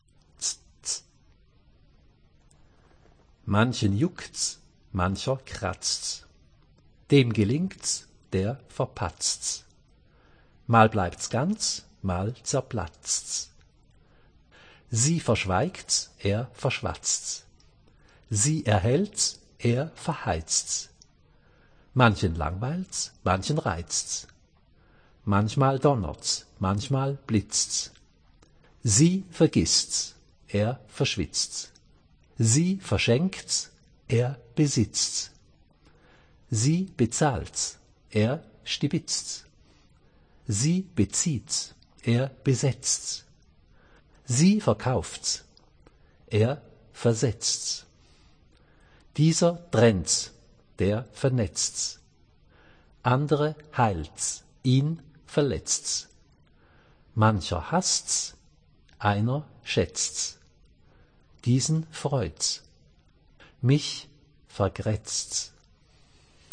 Autorenlesung hören
Audio_Gedicht_Ts-ts.mp3